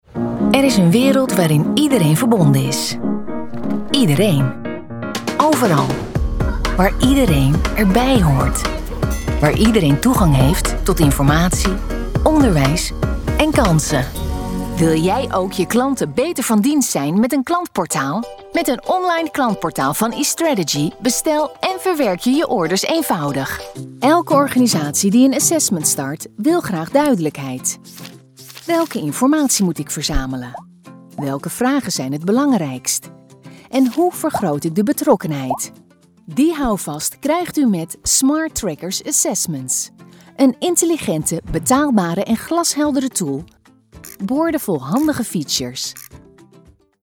Commercial, Reliable, Friendly, Warm, Corporate
Corporate
Besides a friendly and warm voice, she can also sound commercial, cheerful, reliable, businesslike, open, informative, fresh, recognizable, sultry and clear.